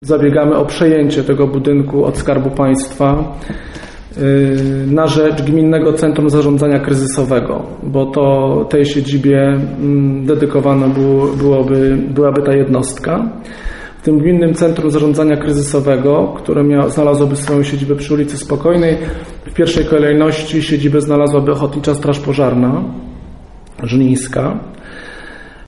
Jakie są plany związane z budynkami mówi burmistrz Robert Luchowski.